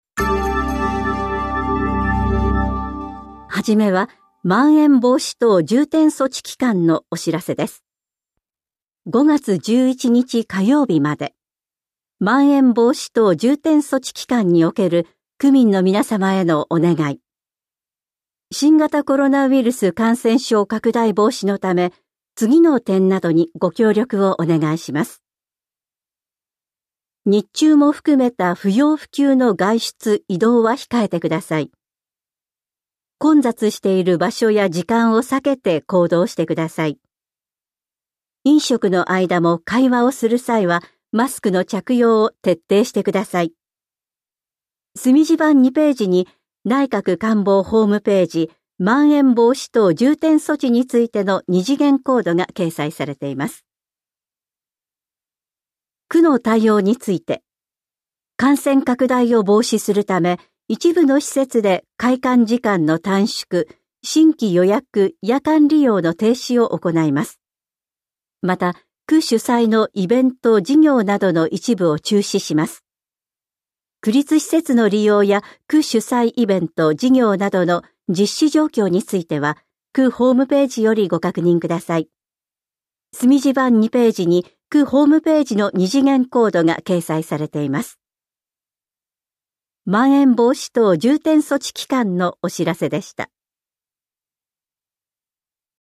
広報「たいとう」令和3年4月20日号の音声読み上げデータです。